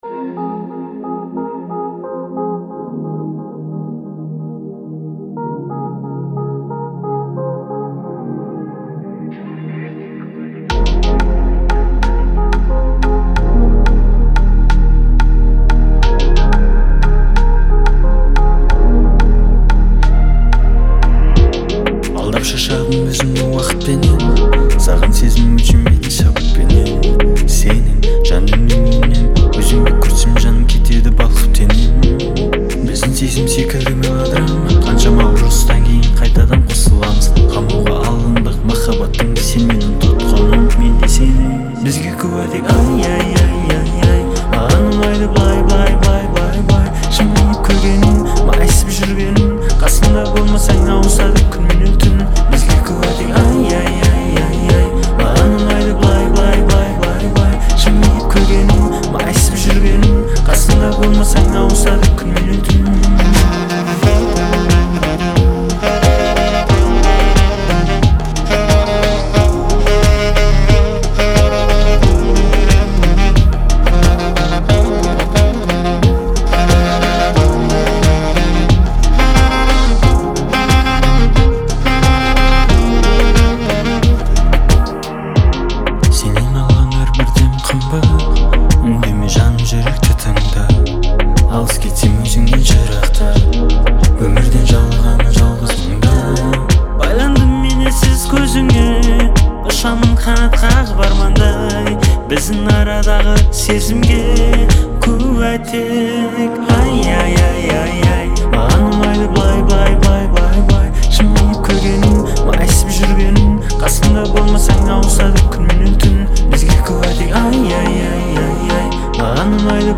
который сочетает в себе элементы поп и традиционной музыки.